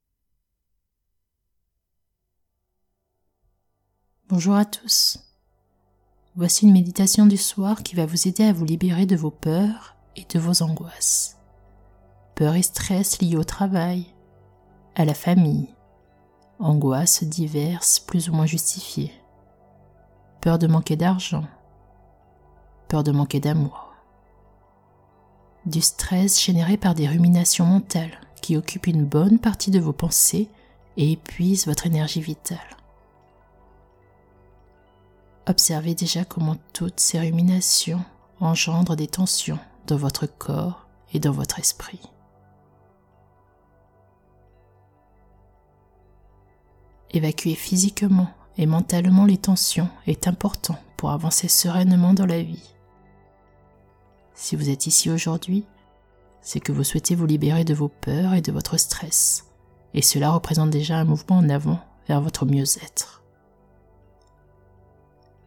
Méditation du soir, libération du stress et des peurs - Bulles de Légèreté
Méditation Tonglen de compassion Écoutez la méditation sur votre plateforme de streaming préférée Durée:1h – Taille: 62,2 Mo Thème de cette séance de méditation Voici une méditation du soir qui va vous aider à vous libérer du stress, des peurs et angoisses
extrait-meditation-liberaton-peurs-stress.mp3